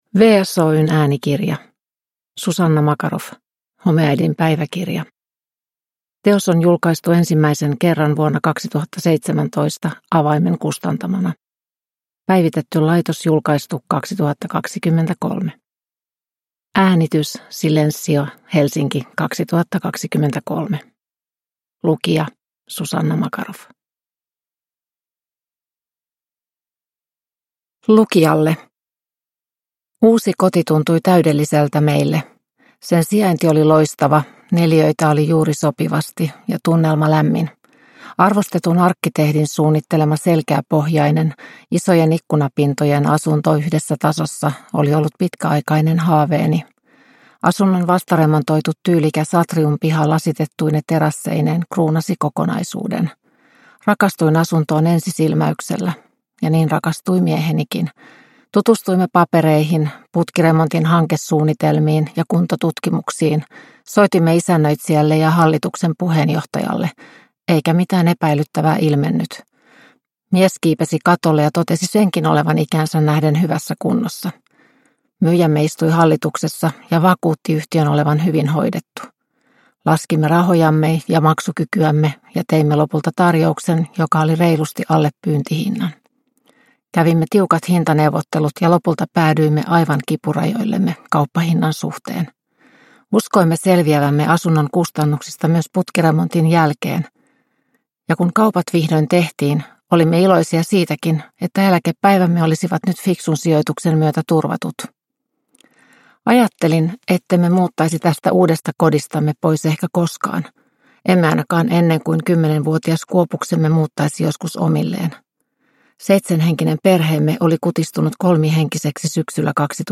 Homeäidin päiväkirja – Ljudbok – Laddas ner